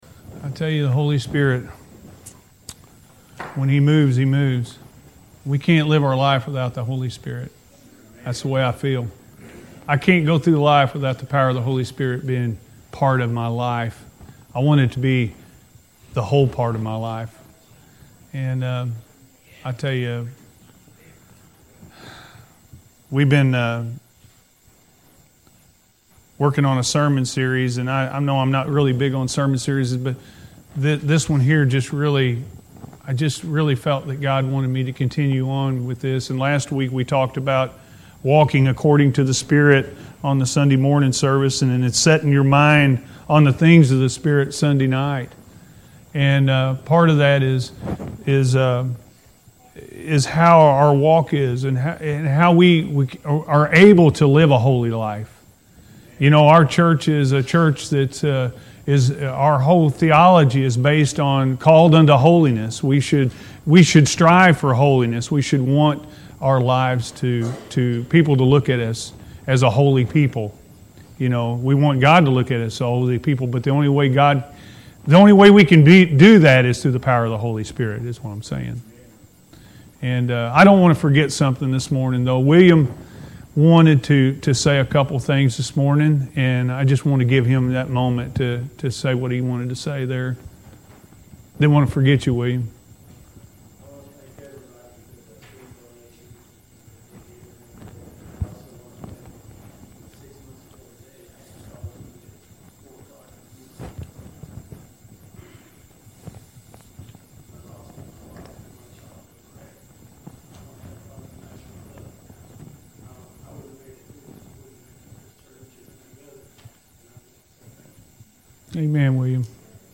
A Life Of Overcoming Sin-A.M. Service